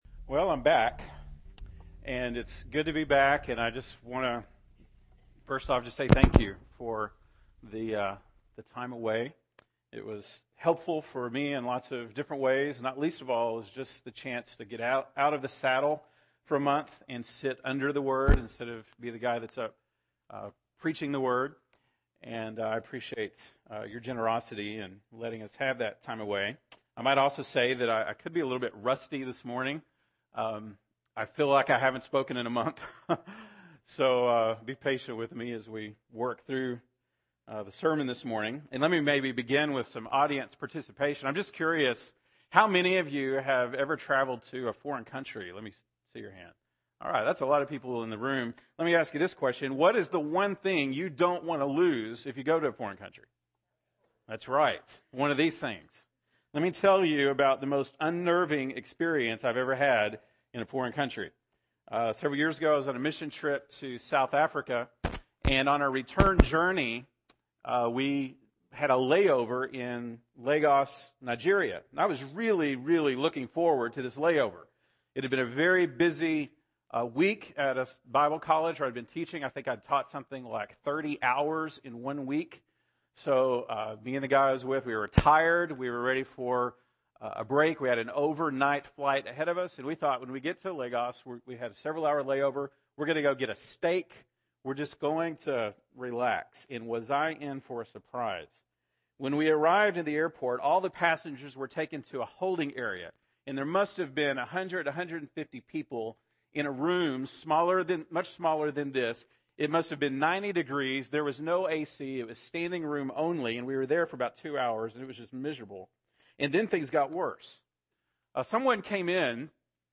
August 2, 2015 (Sunday Morning)